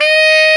Index of /90_sSampleCDs/Roland L-CD702/VOL-2/SAX_Tenor mf&ff/SAX_Tenor ff
SAX TENORF0U.wav